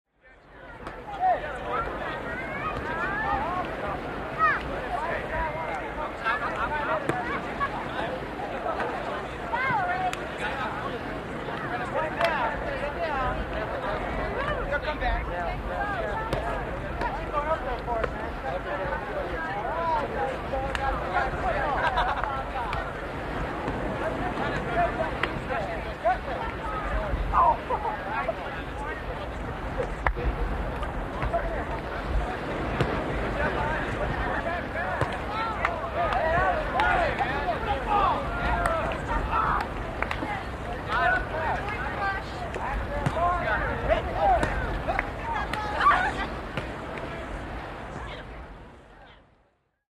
Волейбол на песчаном пляже